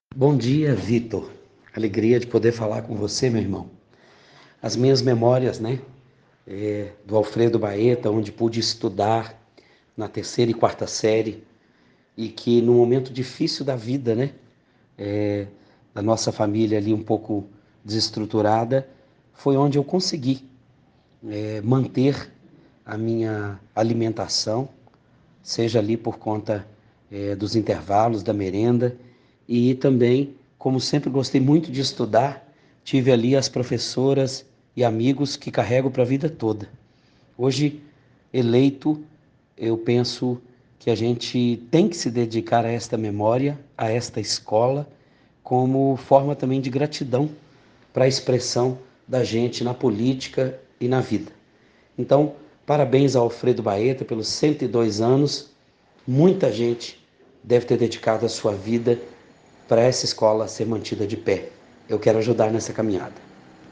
LELECO PIMENTEL É EX-ALUNO DO BAETA – MENSAGEM DO DEPUTADO ESTADUAL ELEITO